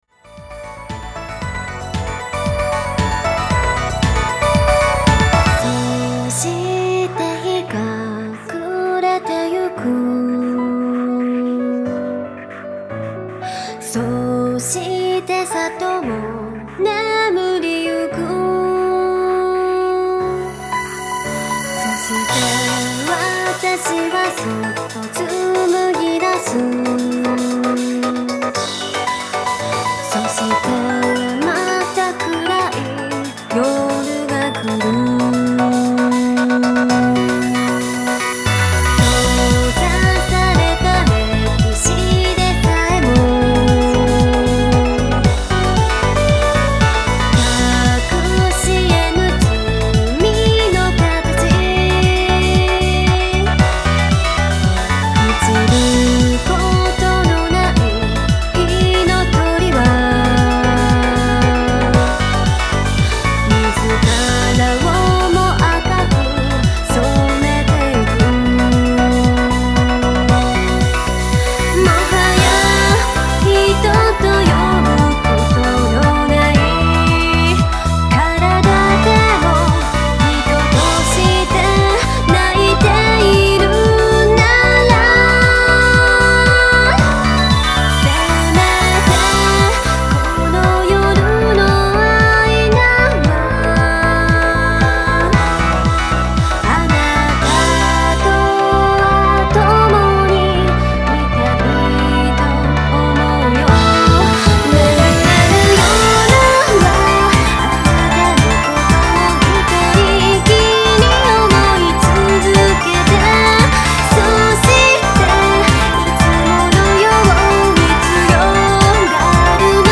今回もボーカル、インスト、共々に様々なジャンルを詰め込んだよりどりみどりの一枚。